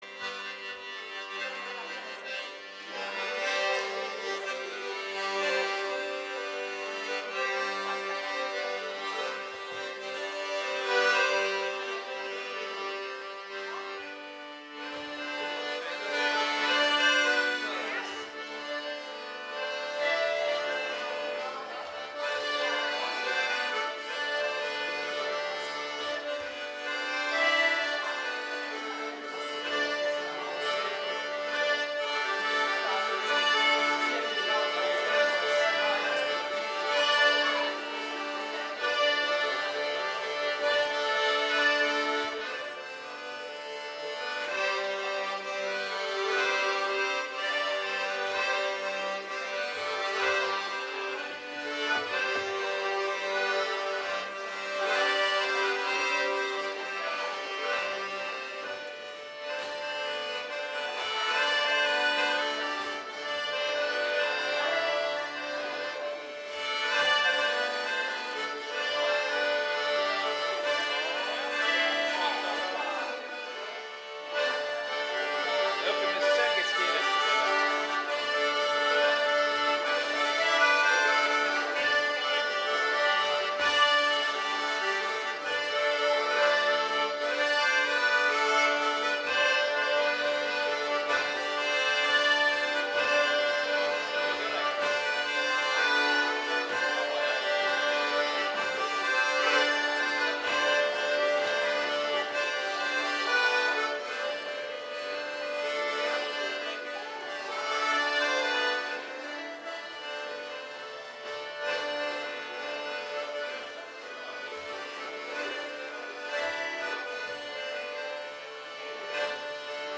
Vanad tantsud - Garais dancis
Läti "Pikk tants" Video Natuke dramaatiline versioon